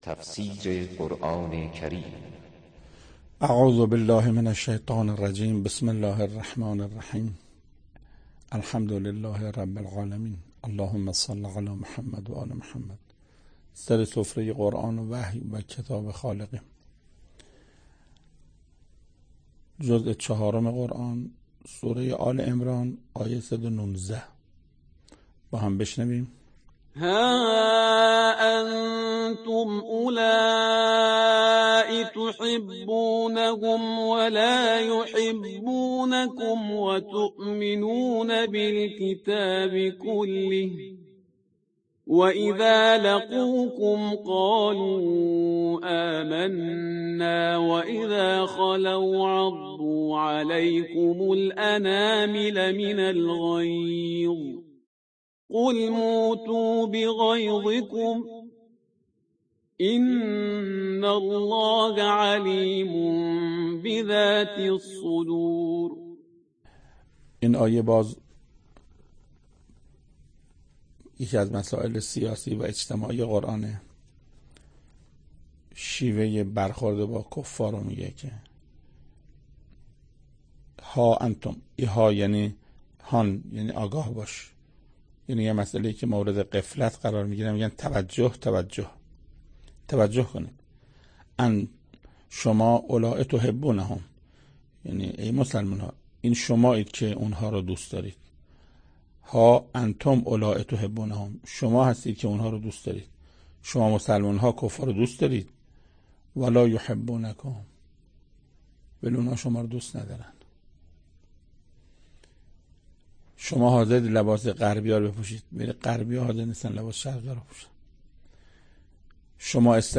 تفسیر صد و نوزدهمین آیه از سوره مبارکه آل عمران توسط حجت الاسلام استاد محسن قرائتی به مدت 9 دقیقه